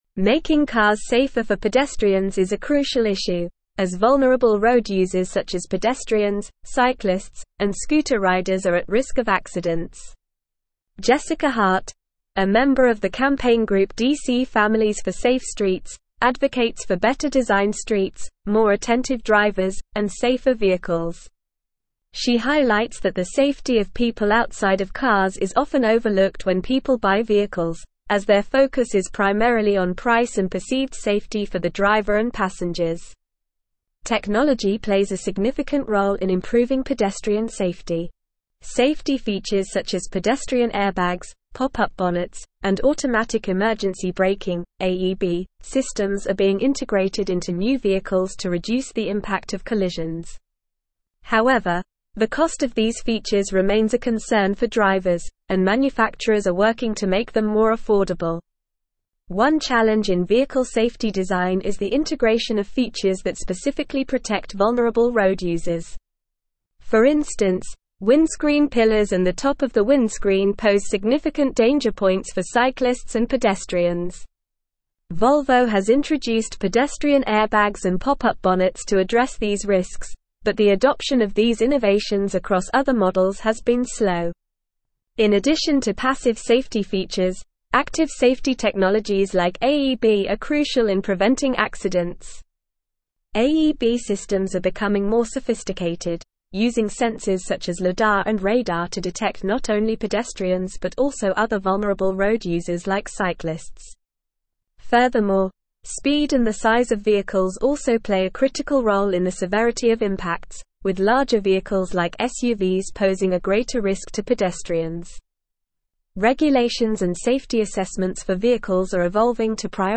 Normal
English-Newsroom-Advanced-NORMAL-Reading-Advocating-for-Safer-Streets-Protecting-Vulnerable-Road-Users.mp3